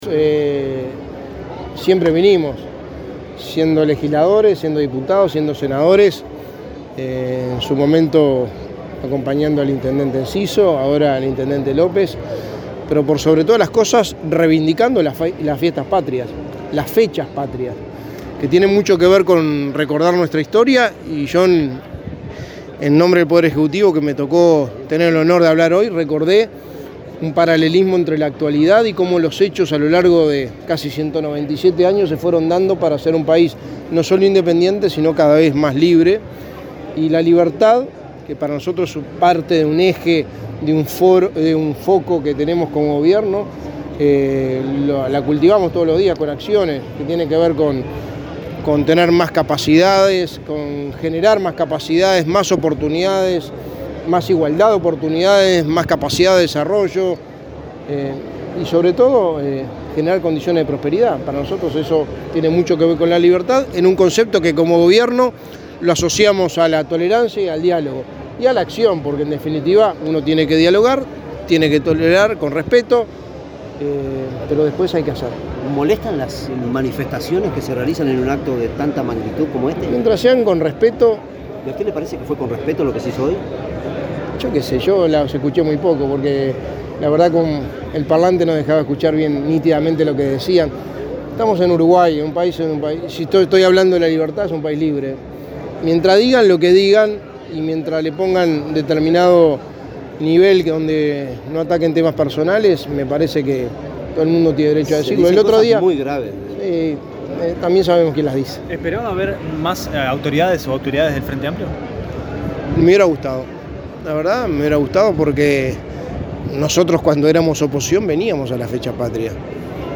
Declaraciones a la prensa del secretario de Presidencia, Álvaro Delgado
El secretario de Presidencia, Álvaro Delgado, dialogó con la prensa luego de participar del acto de conmemoración de un nuevo aniversario de la